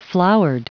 Prononciation du mot flowered en anglais (fichier audio)
Prononciation du mot : flowered